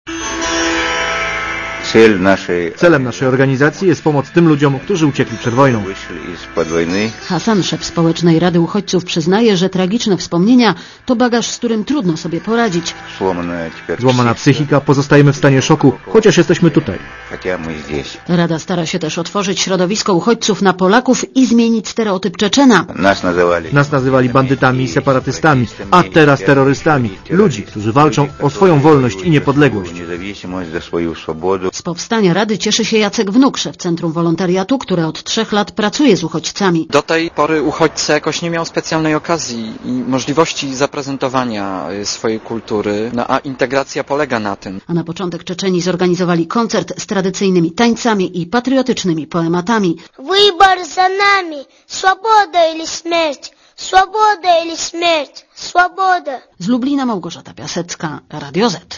Relacja reporterów Radia ZET